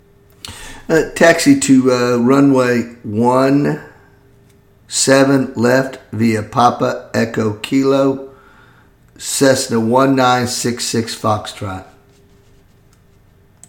Aviation Radio Calls
06a_PilotRunwayOneSevenLeftViaPapaEchoKilo.mp3